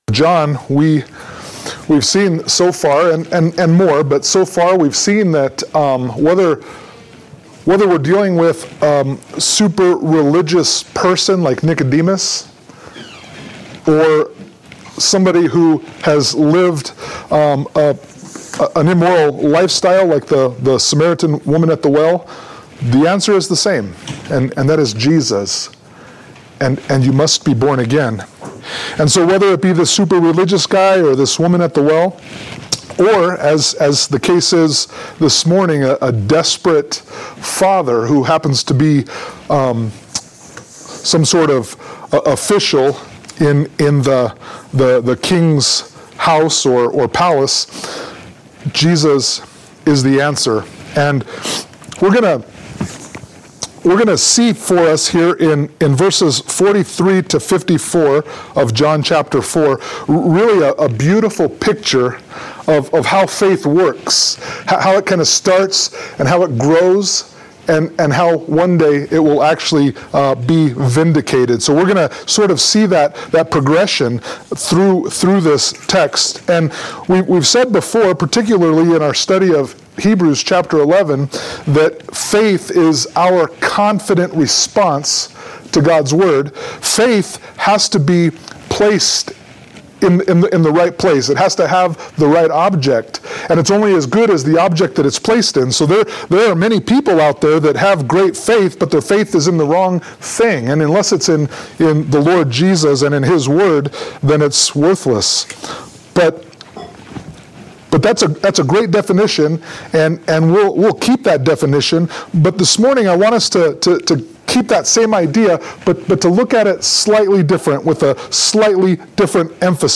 John 4:43-54 Service Type: Sunday Morning Worship « John 4:1-42